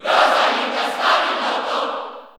Category: Crowd cheers (SSBU) You cannot overwrite this file.
Rosalina_&_Luma_Cheer_Italian_SSB4_SSBU.ogg